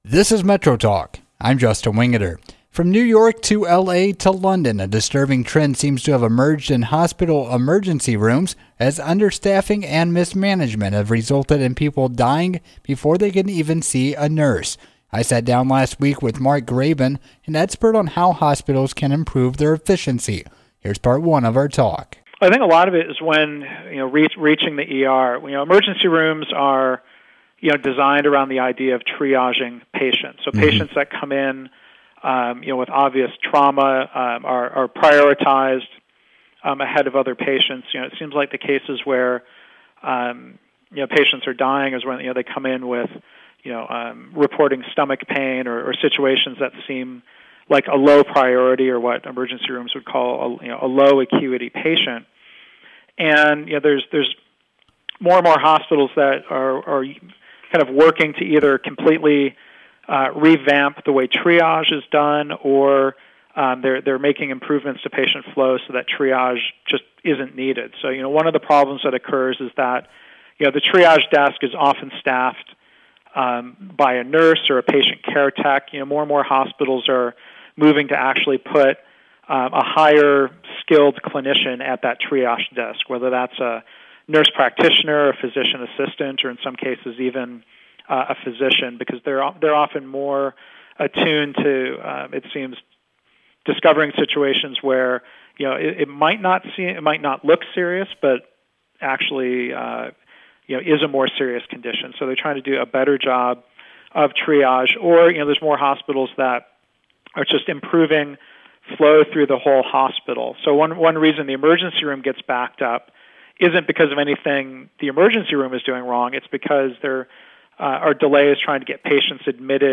• WSIE 88.7 FM NPR Southern Illinois, Interviewed about emergency department improvements –